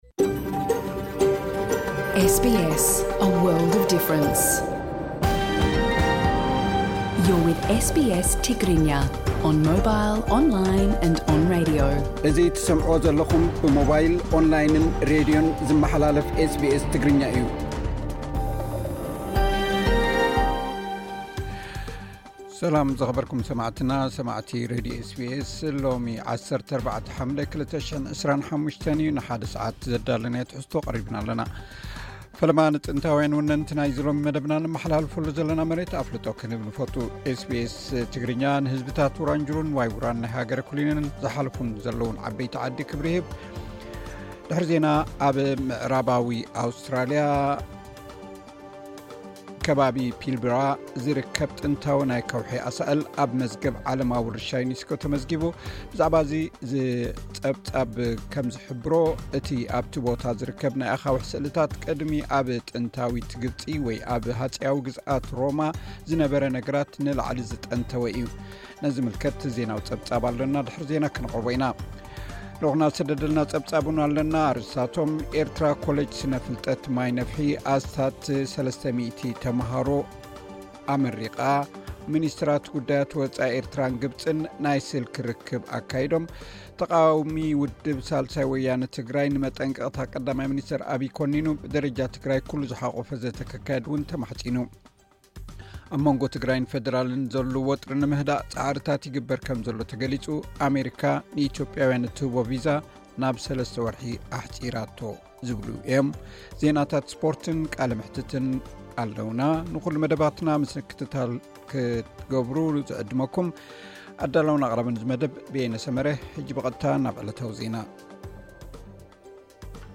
ዕለታዊ ዜና ኤስ ቢ ኤስ ትግርኛ (14 ሓምለ 2025)